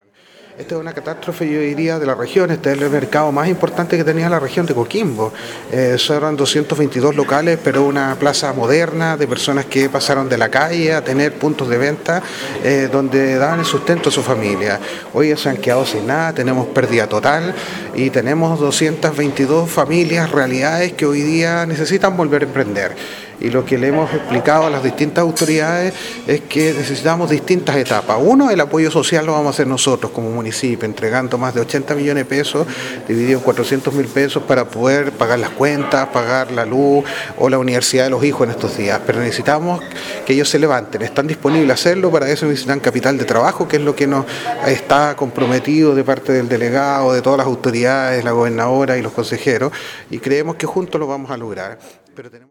El alcalde Denis Cortés, agradeció en primer término a todas las autoridades regionales que se han puesto a disposición para enfrentar esta situación con voluntad y con empatía con este drama que afecto a 222 familias de Illapel.
Denis-Cortes-Alcalde-de-Illapel.-mp3cut.net_.mp3